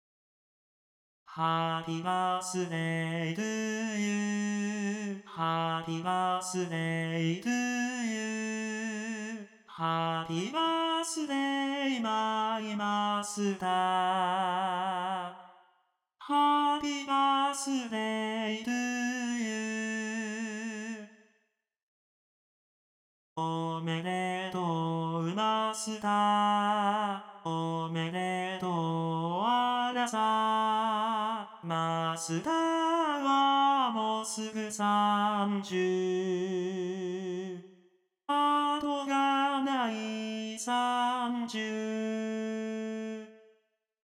KAITOさんに
ハッピーバースデー歌ってもらいました。
よく分からなかったから、とりあえずベタ打ち･･･だったと思う。
きっとベタ打ち。ちゃんと聞き取れる･･･かな？